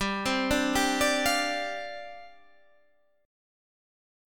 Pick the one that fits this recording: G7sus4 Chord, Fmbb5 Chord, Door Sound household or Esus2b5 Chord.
G7sus4 Chord